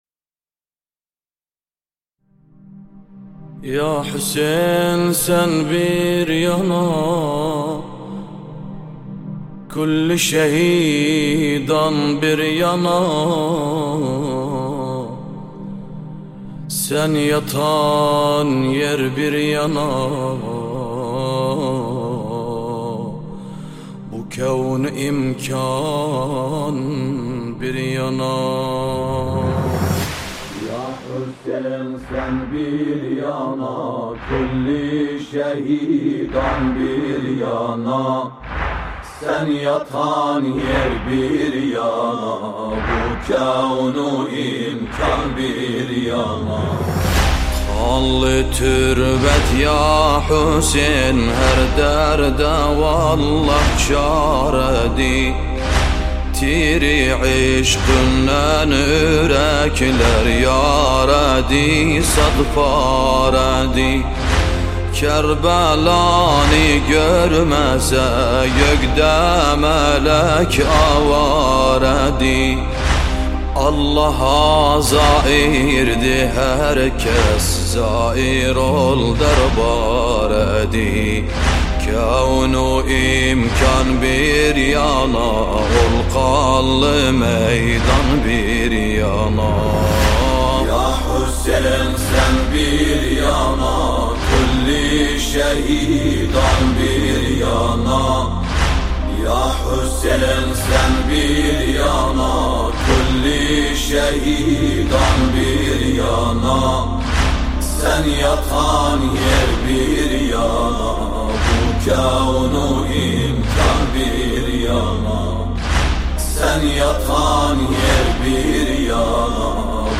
اجرا شده در ماه محرم 1442 قــ - 1399 شـــ - از کرکوک عراق - حسینیه اصحاب کساء/ داقوق